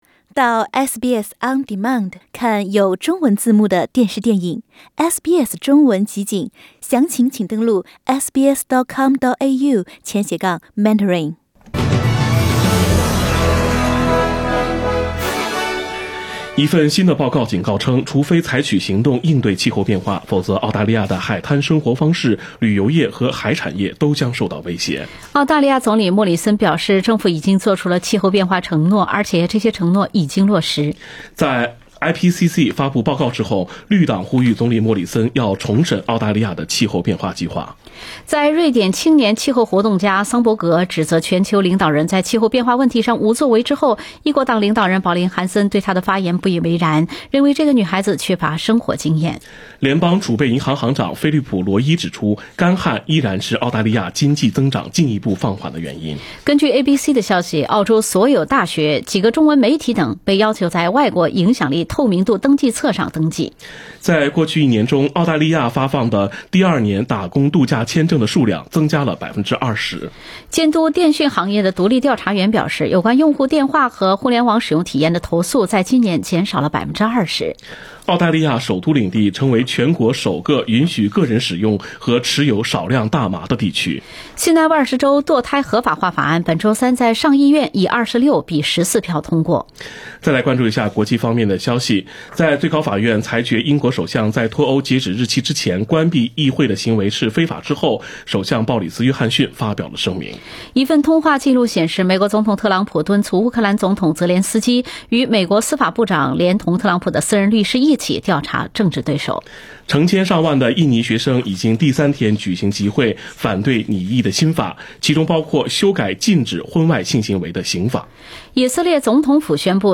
SBS早新闻（9月26日）